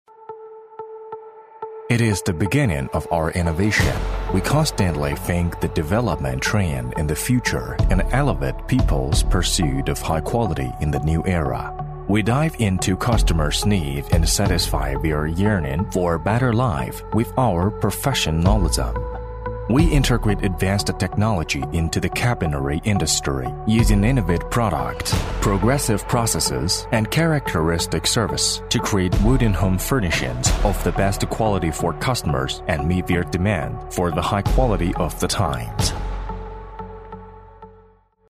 男386-英文广告《拉诺伊橱柜》质感
男386-中英双语 高端大气
男386-英文广告《拉诺伊橱柜》质感.mp3